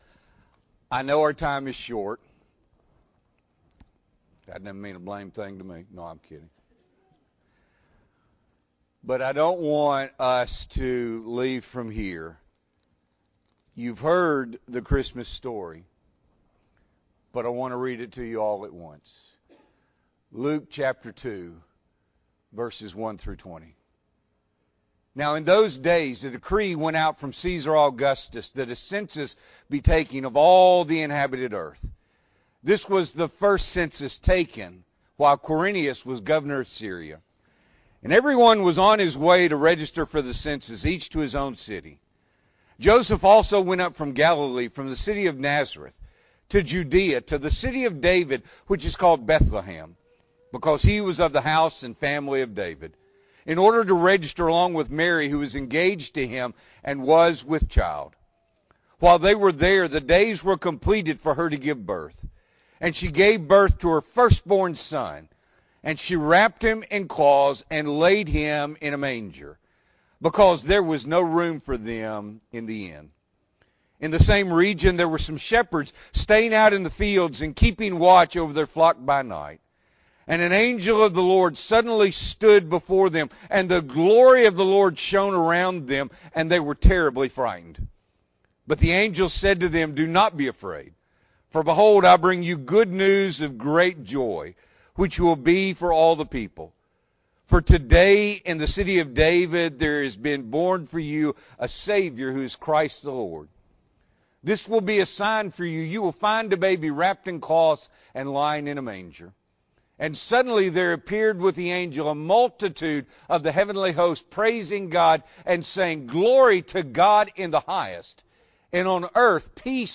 December 19, 2021 – Morning Worship